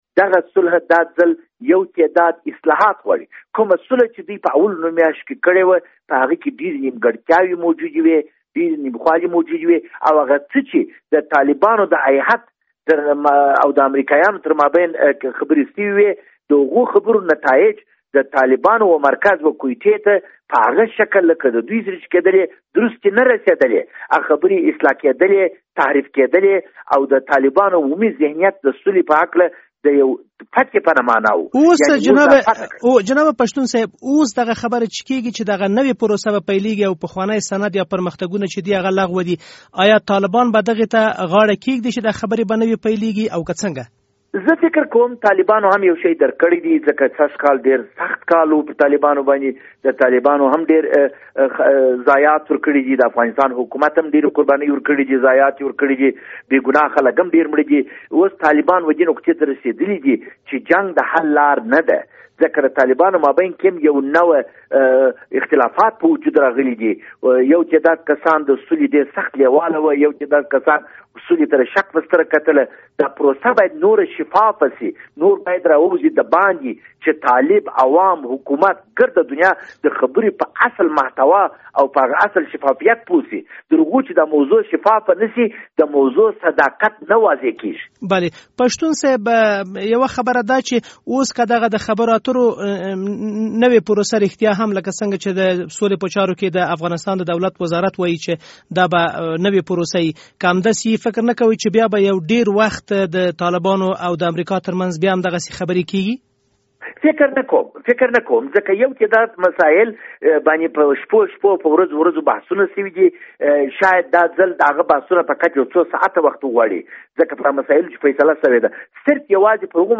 مرکه
د ولسي جرګې پخوانی غړی او د سیاسي چارو شنونکی خالد پښتون
خالد پښتون سره مرکه